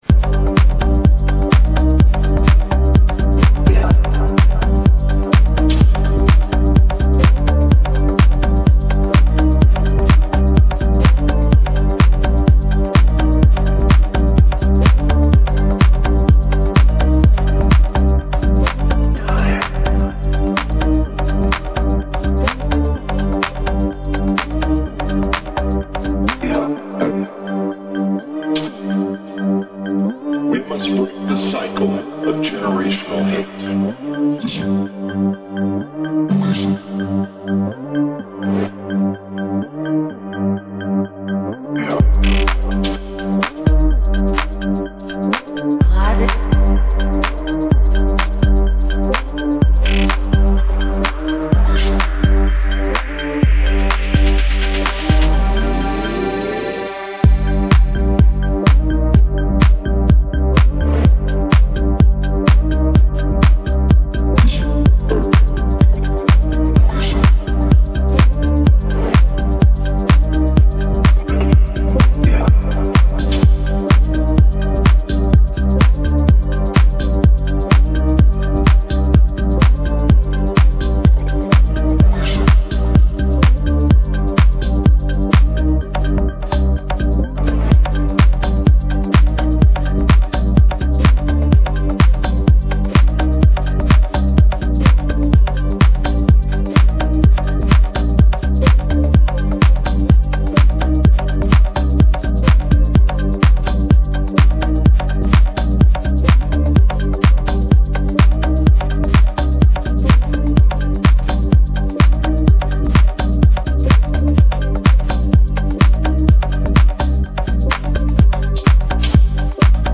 an atmospheric, groovy house with many small nuances.